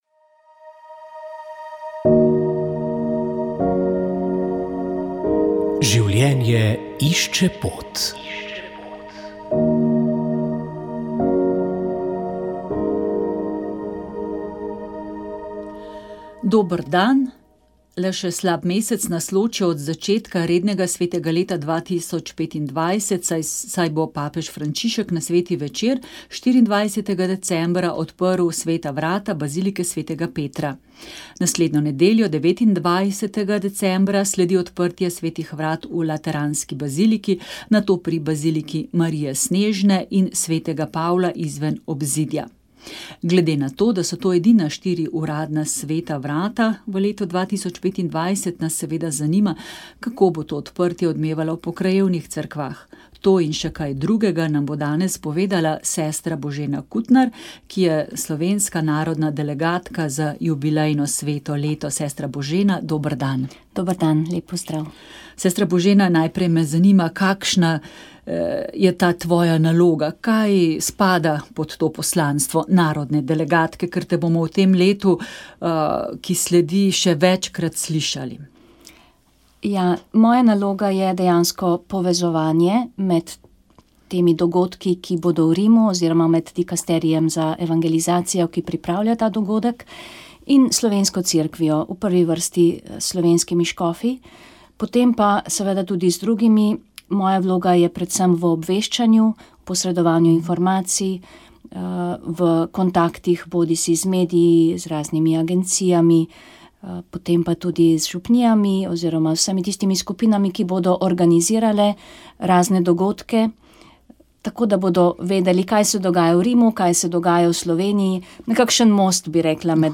Ali je opozicija sposobna iskrenega sodelovanja, kam nas pelje vlada pod vodstvom Roberta Goloba, nas čakajo predčasne volitve in kako ocenjuje predlog Katedrale Svobode, da bi Slovenija dobila krizno in reformno vlado in kateri bodo osrednji poudarki sobotnega programskega kongresa, je zgolj nekaj vprašanj, ki smo jih zastavili predsedniku Nove Slovenije. V oddaji je bilo mogoče slišati tudi razmišljanje diplomata dr. Ernesta Petriča na okrogli mizi Katedrale Svobode. Spregovoril je o vojni v Ukrajini in konfliktu na Bližnjem vzhodu in ob tem pozval k iskanju skupnih rešitev tudi v Sloveniji.